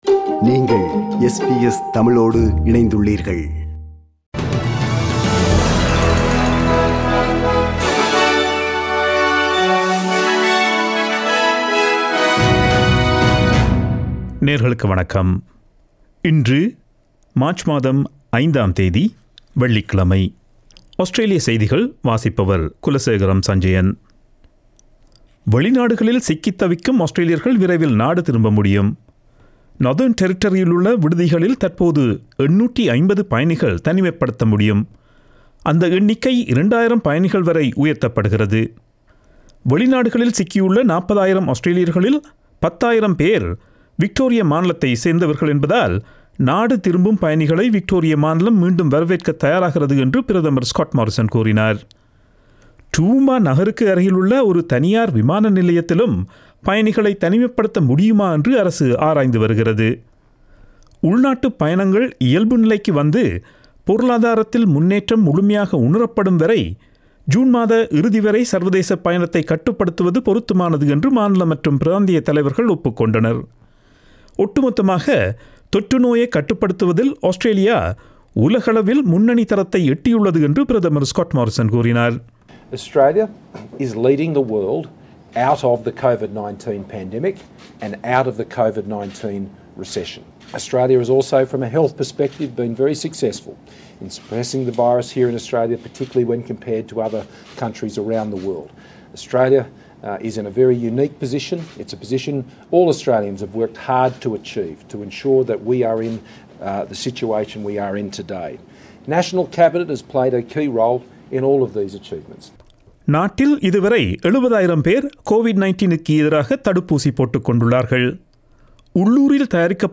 Australian news bulletin for Friday 05 March 2021.